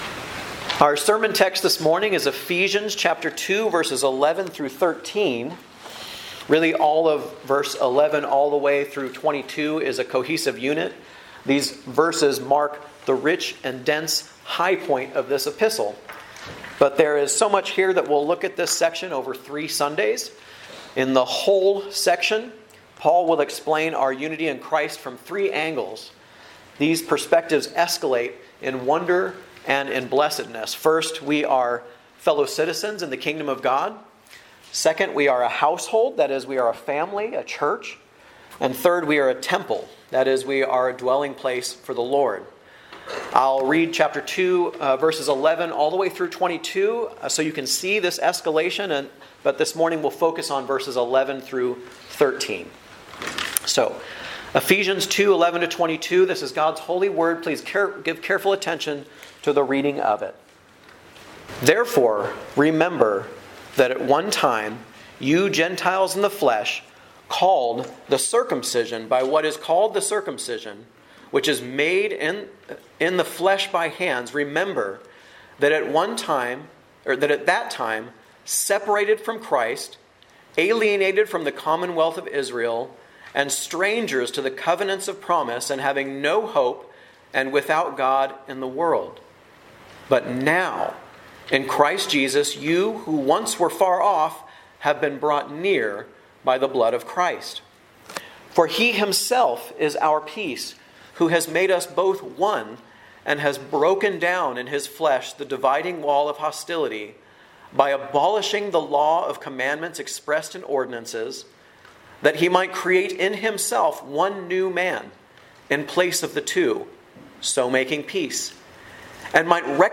A message from the series "Guest Preacher."